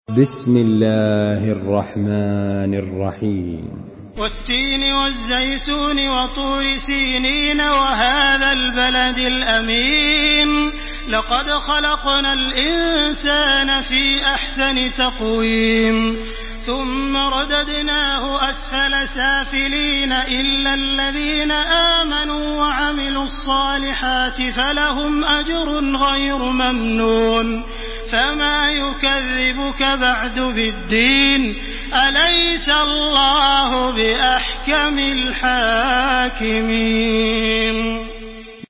تحميل سورة التين بصوت تراويح الحرم المكي 1429
مرتل حفص عن عاصم